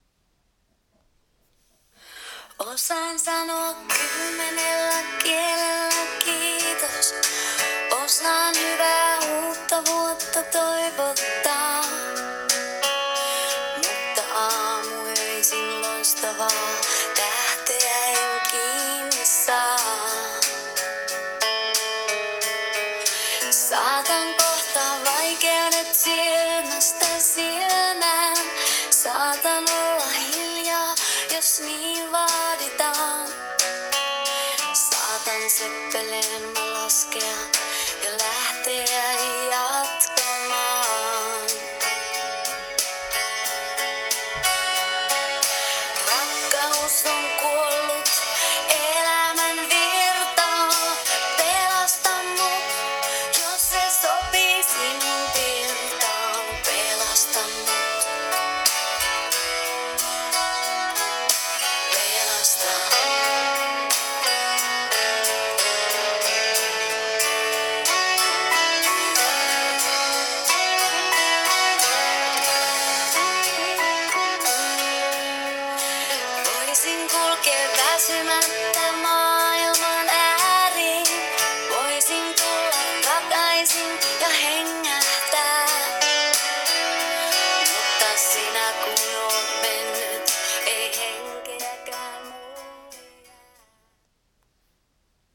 Rakkauden haudalla A duuri.m4a